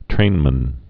(trānmən)